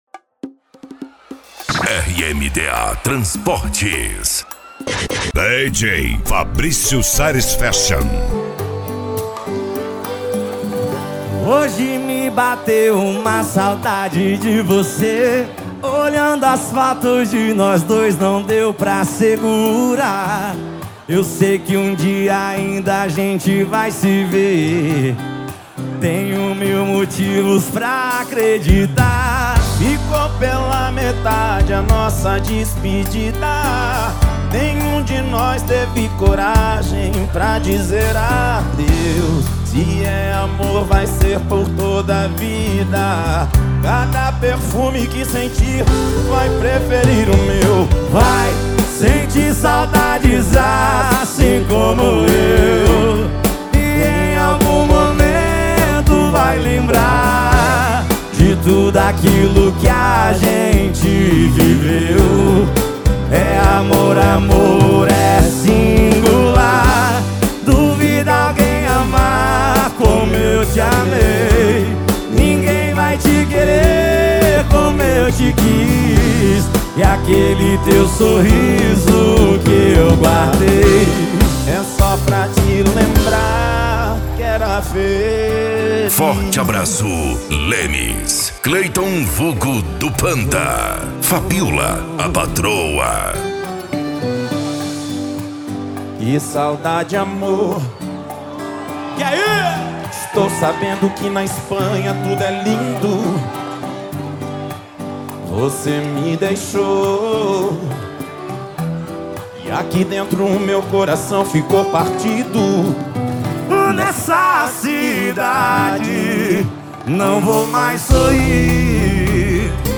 Funk
SERTANEJO